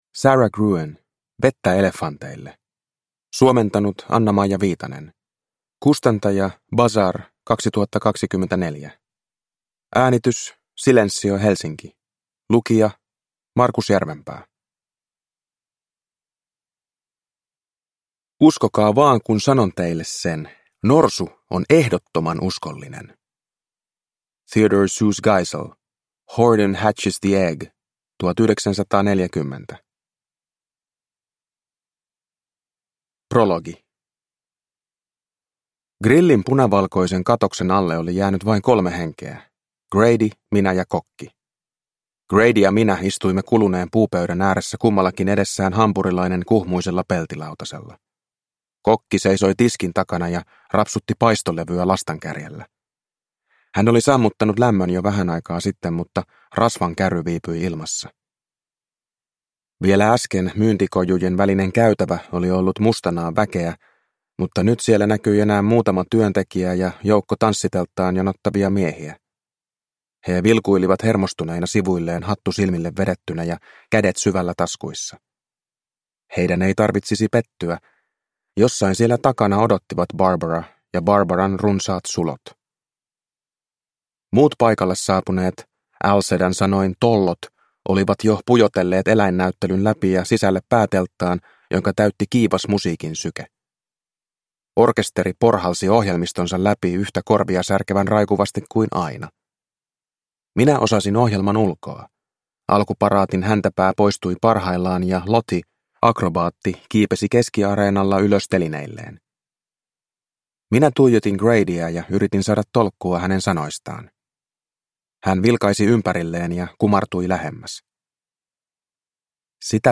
Vettä elefanteille – Ljudbok